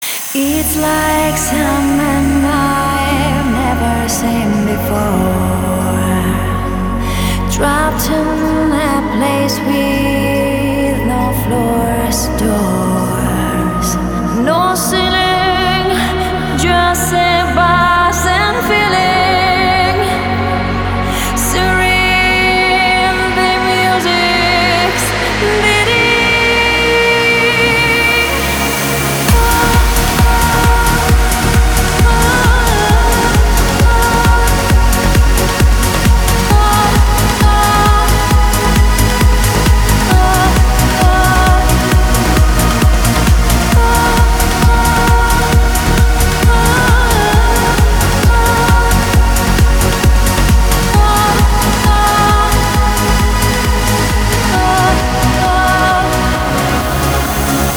• Качество: 320, Stereo
красивые
женский вокал
Trance
vocal